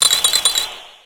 Cri de Sorbébé dans Pokémon X et Y.